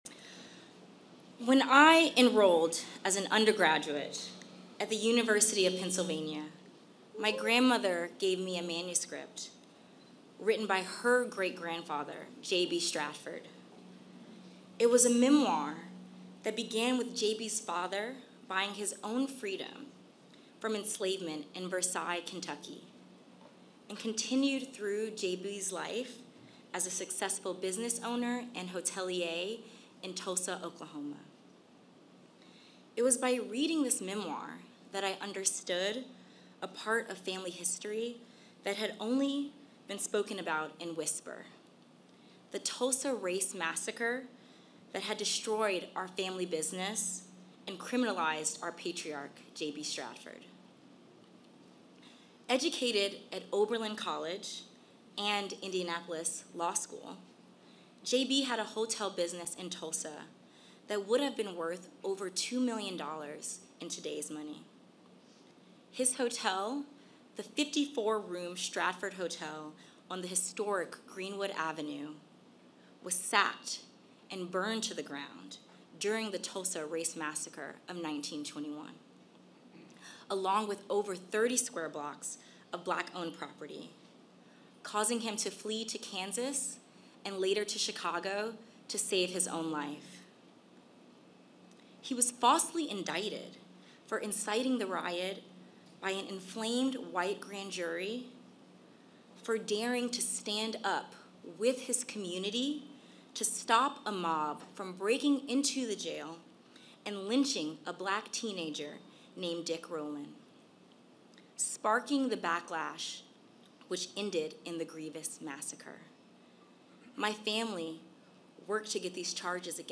Presidential Speeches
President_Biden_Remarks_Commemorating_Anniversary_Tulsa_Race_Massacre.mp3